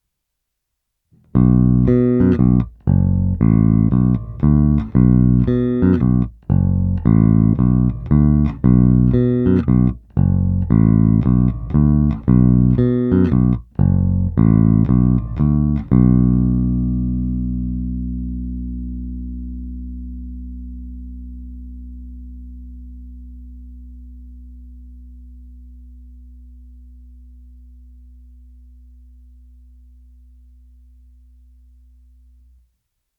Zvuk je vpravdě monstrózní.
Není-li uvedeno jinak, následující nahrávky jsou provedeny rovnou do zvukové karty, jen normalizovány, jinak ponechány bez úprav.
Oba snímače